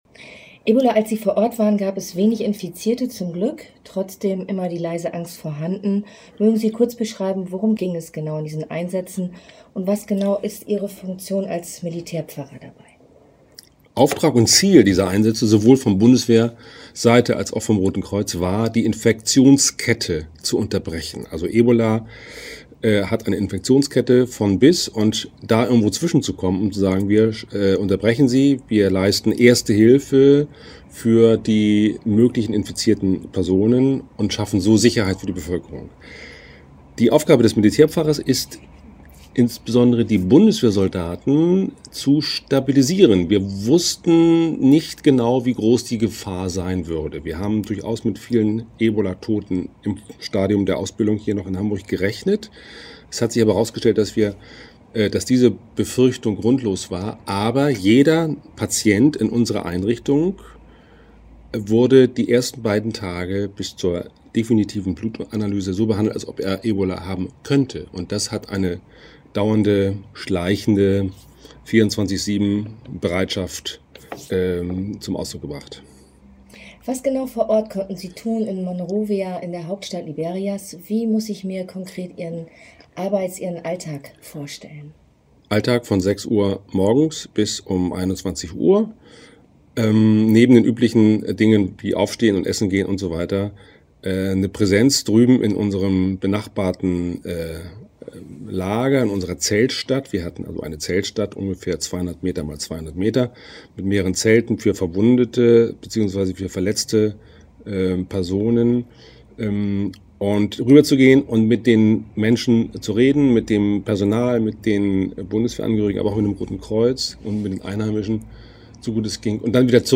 (Länge: 11:21 Minuten - Download des Interviews)